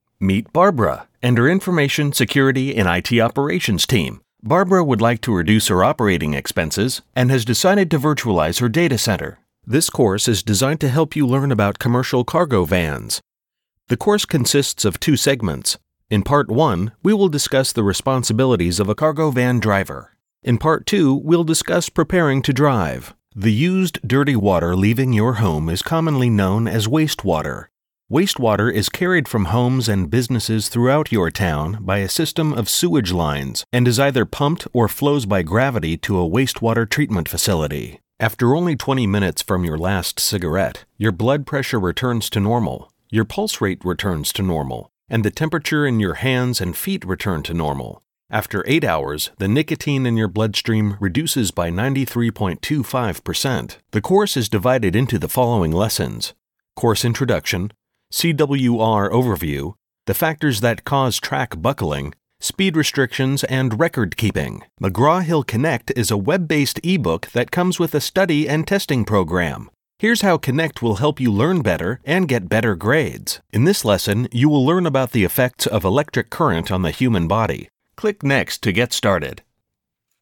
Sprechprobe: eLearning (Muttersprache):
Inviting and friendly, relaxed yet engaging, authoritative, energetic and sincere.